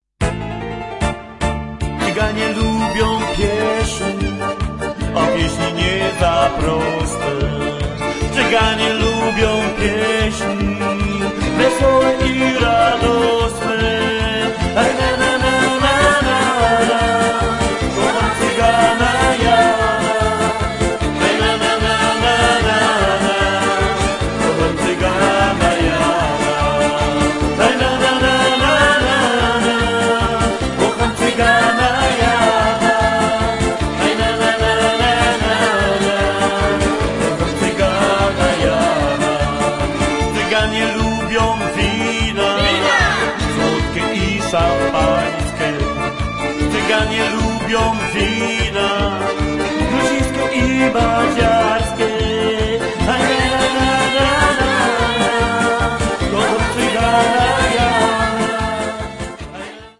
Organ
Violin
Accordion
Backup Singers